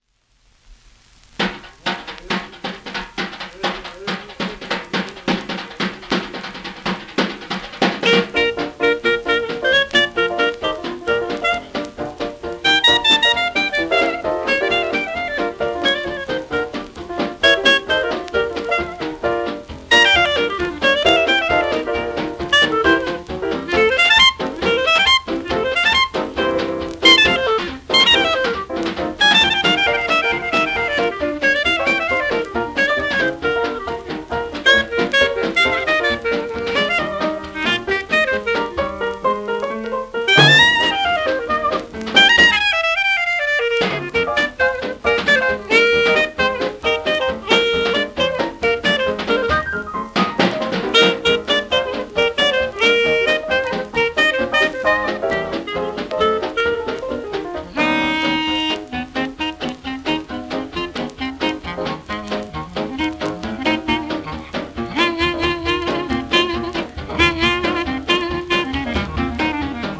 1936年の録音